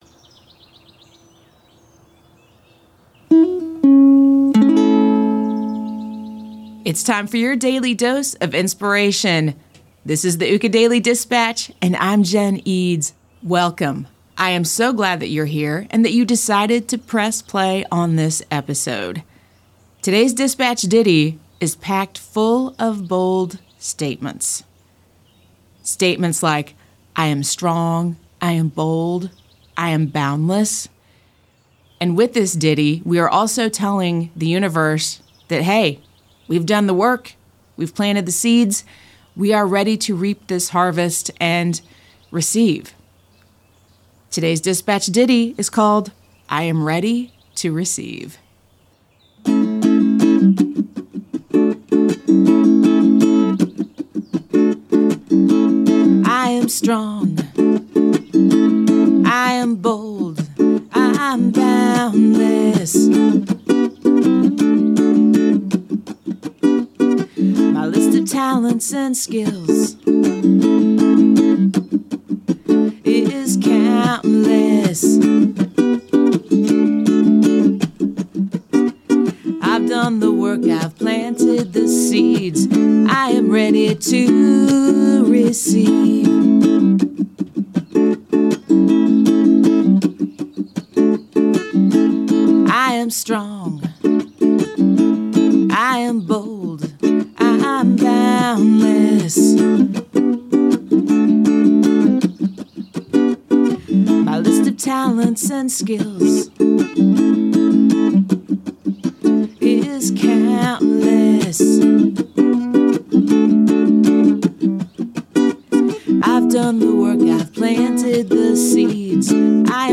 Today's ditty is packed with bold statements.